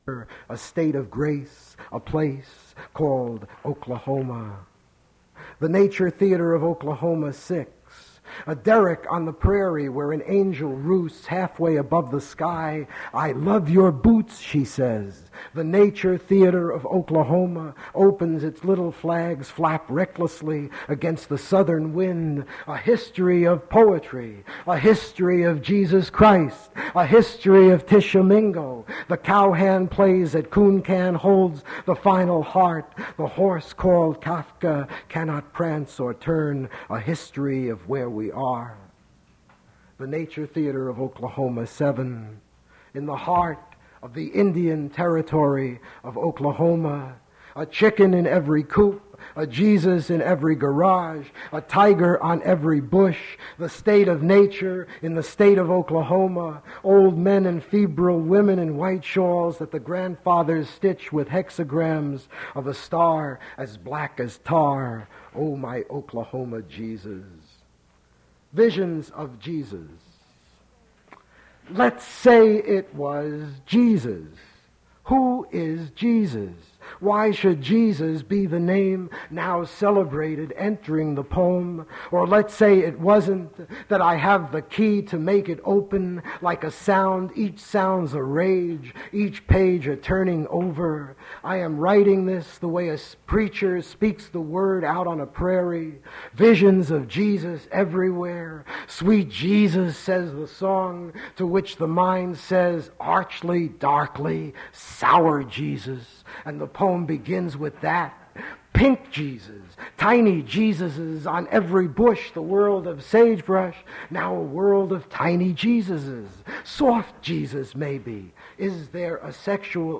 Poetry reading featuring Jerome Rothenberg
• Jerome Rothenberg poetry reading at Duff's Restaurant. Recording Index: 00:00 The Nature Theater of Oklahoma; 01:07 Visions of Jesus; 06:33 Closing Remarks
• mp3 edited access file was created from unedited access file which was sourced from preservation WAV file that was generated from original audio cassette.